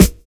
DISCO 15 SD.wav